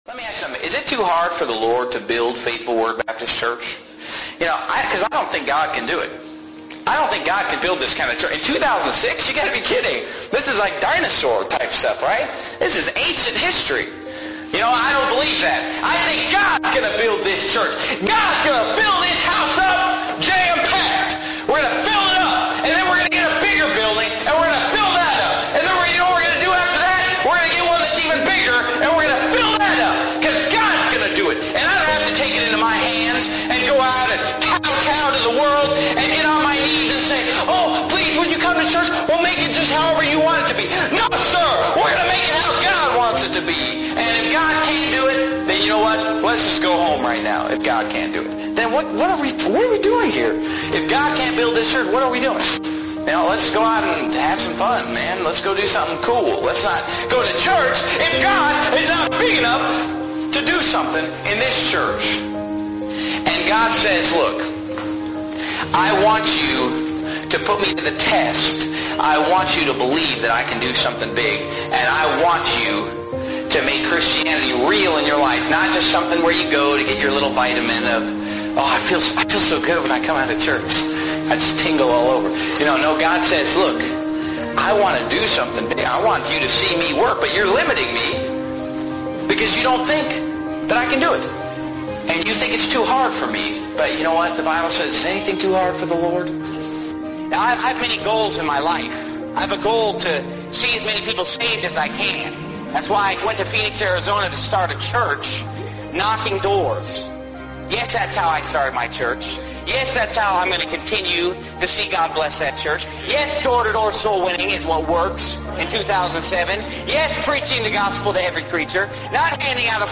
Epic Preaching
Epic_Preaching.mp3